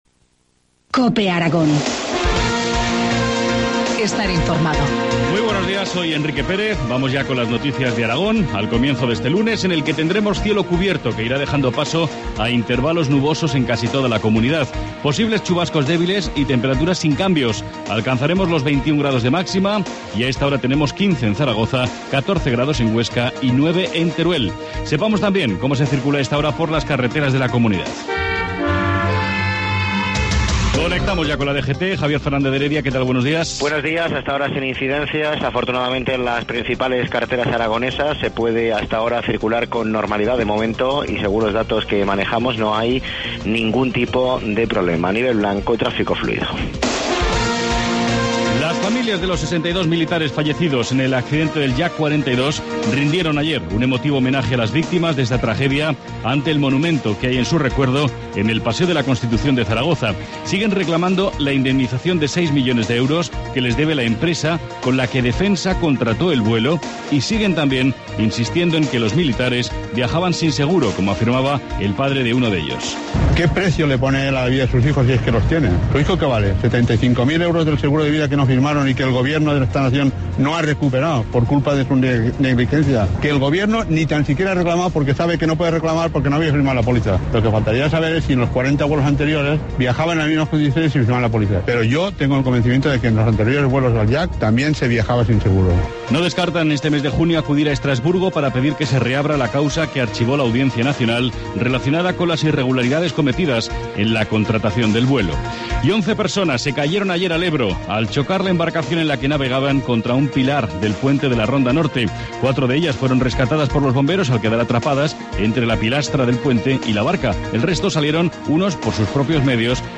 Informativo matinal, lunes 7 mayo 7,25 horas